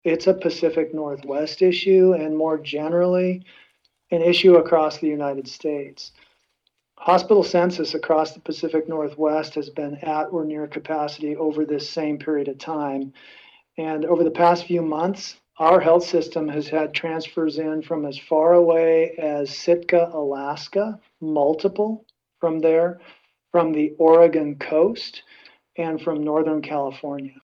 During a press availability, agency officials called the situation a ‘triple-demic’ of flu, respiratory syncytial virus (RSV), and COVID-19.